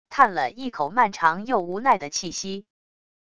叹了一口漫长又无奈的气息wav音频